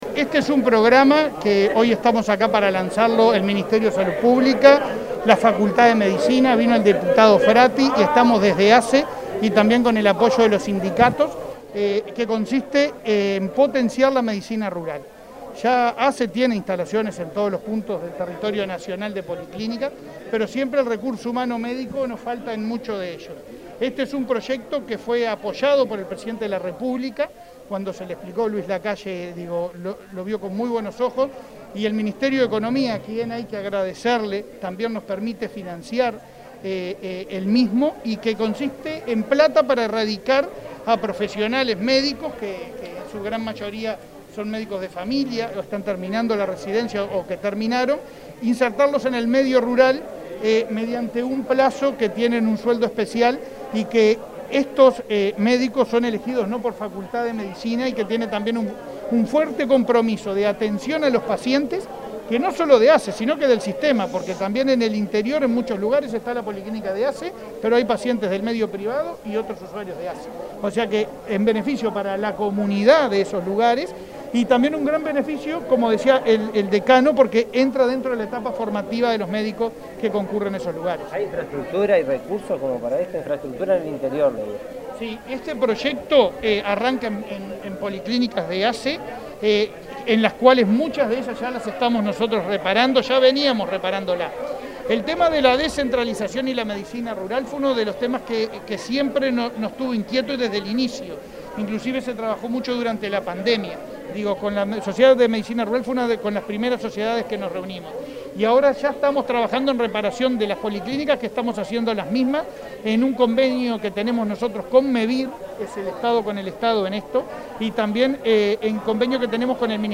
Declaraciones del presidente de ASSE, Leonardo Cipriani, a la prensa
El presidente de ASSE, Leonardo Cipriani, participó del lanzamiento del Plan de Medicina Rural, este viernes 12 en Salto, que se desarrollará en